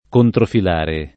[ kontrofil # re ]